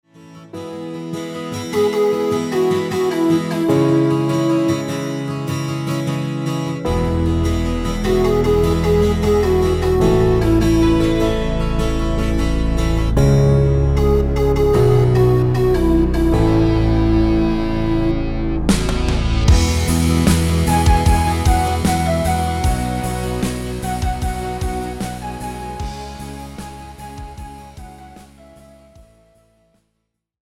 Žánr: Pop
BPM: 151
Key: E
MP3 ukázka s ML